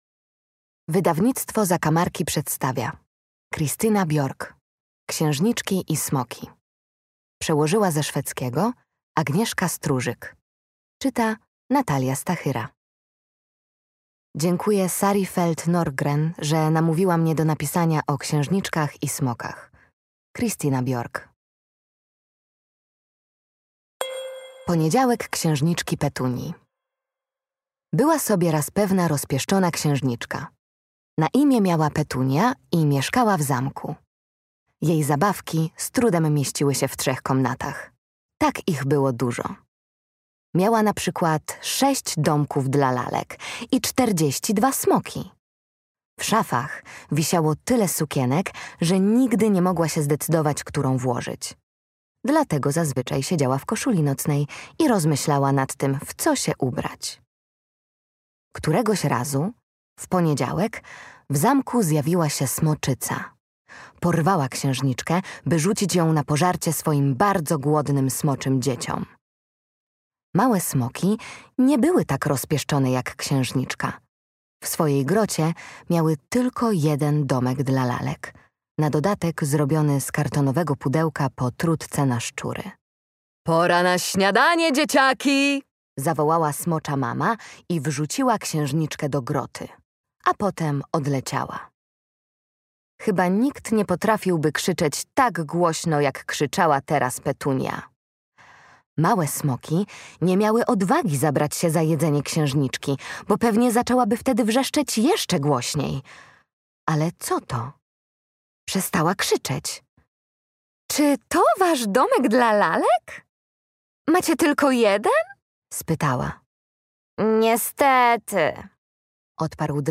Księżniczki i smoki - Christina Björk - audiobook